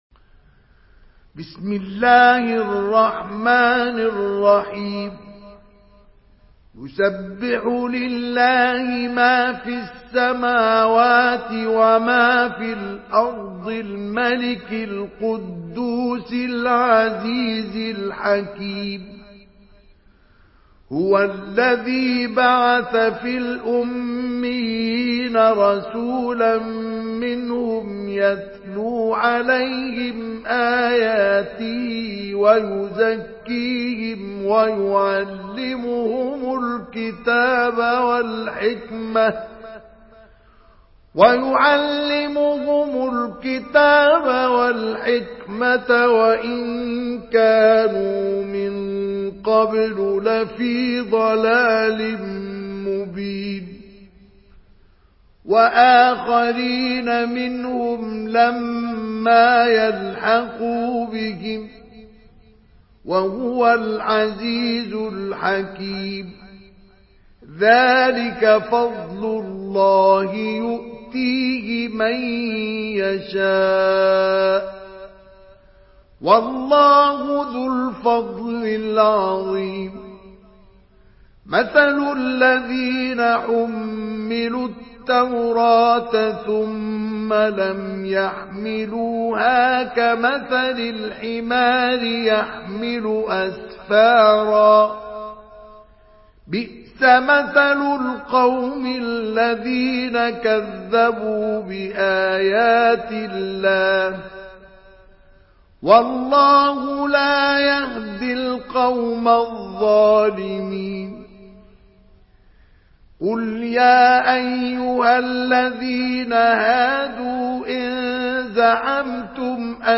سورة الجمعة MP3 بصوت مصطفى إسماعيل برواية حفص
مرتل